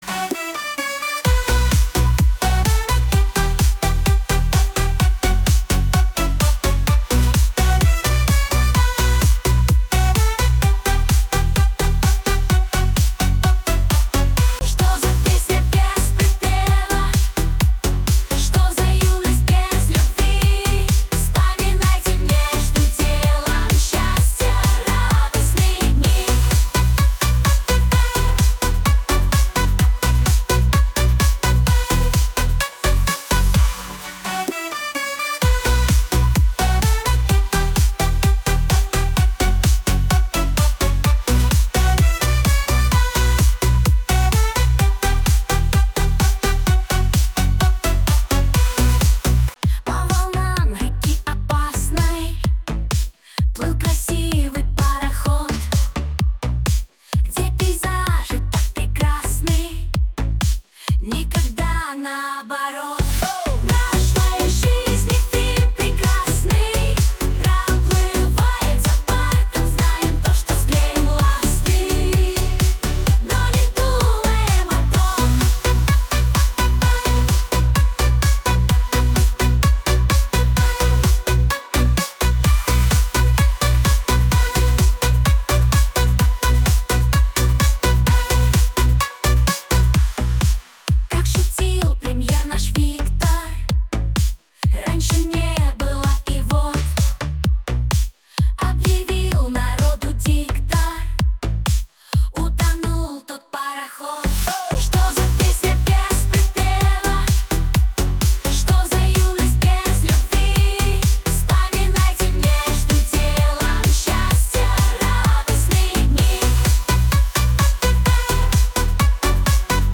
Музыкальный хостинг: /Бардрок